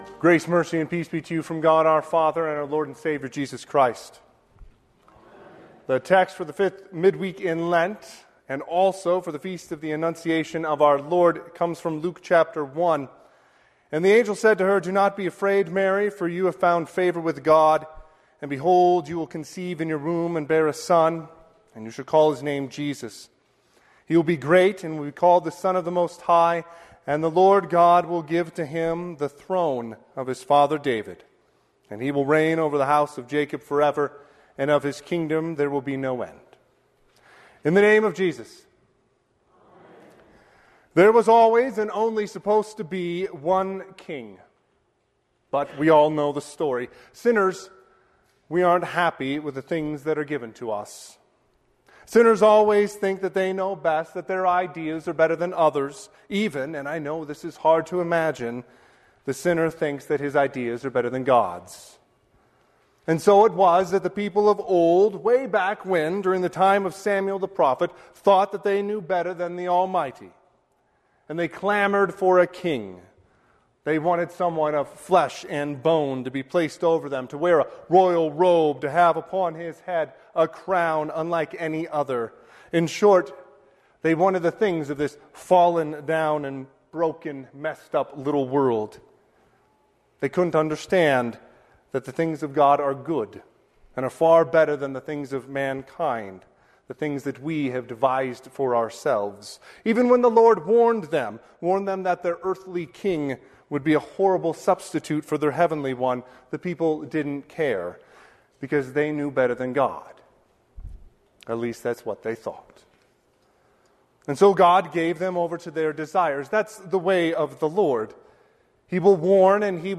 Sermon - 3/25/2026 - Wheat Ridge Evangelical Lutheran Church, Wheat Ridge, Colorado